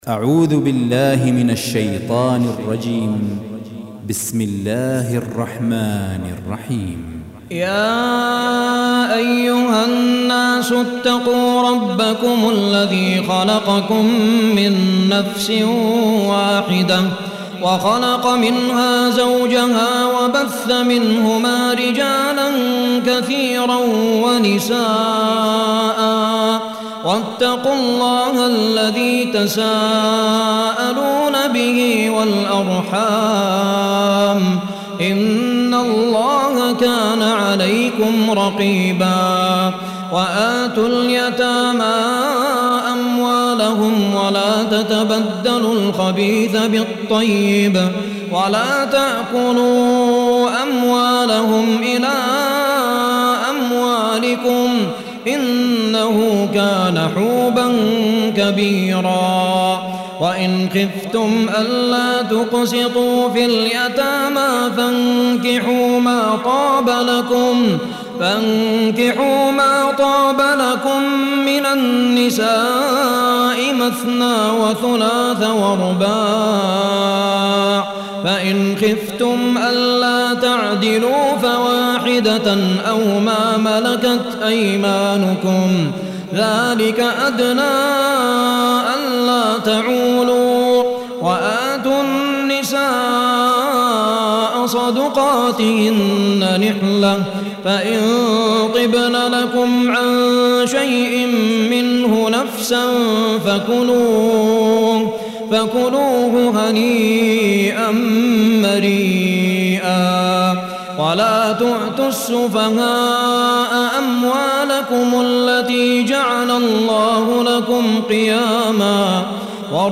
المصحف المرتل
بجودة عالية